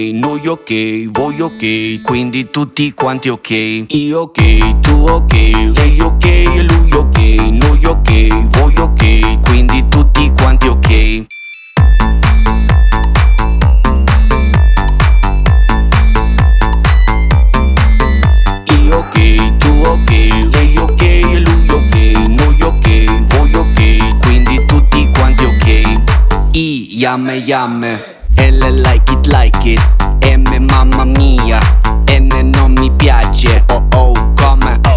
2025-08-22 Жанр: Поп музыка Длительность